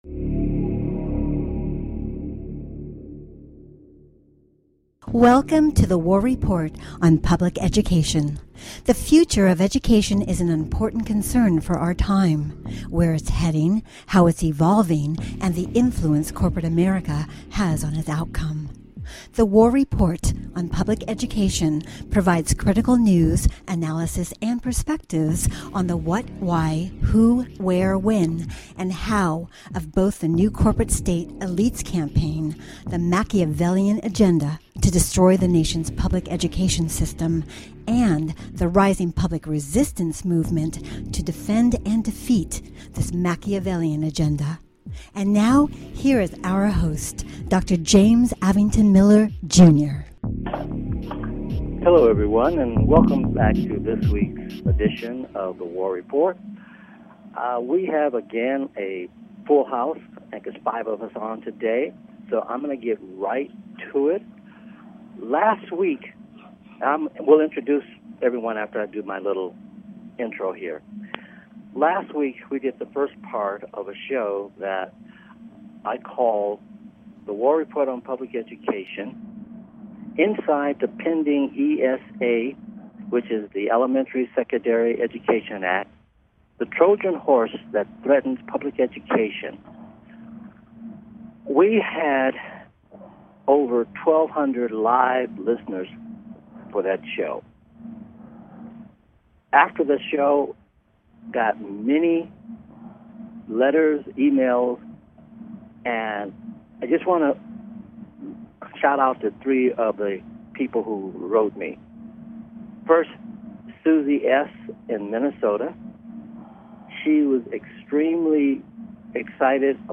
Headlined Show, The War Report on Public Education June 27, 2015